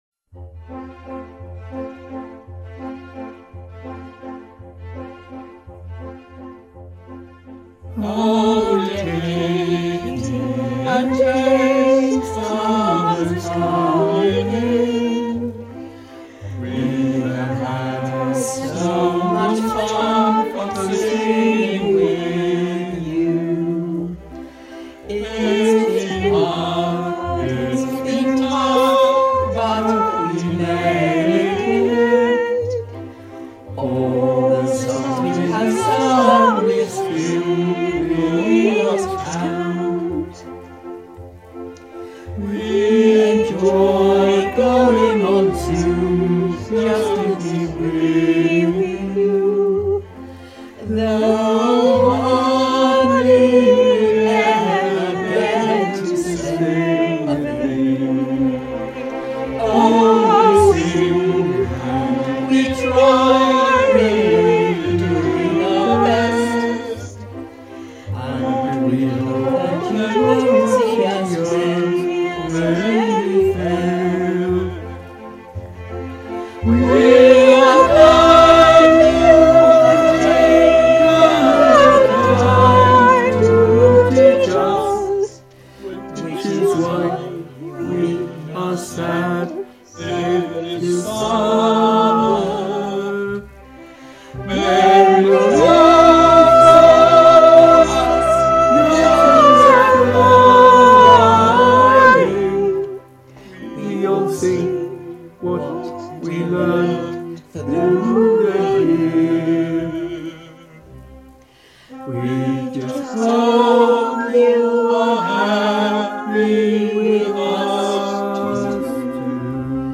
We, The Merry Band of Rebel Singers (of sorts) of MCC did a one time off performance.